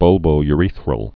(bŭlbō-y-rēthrəl)